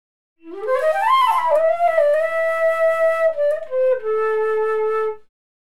flute.wav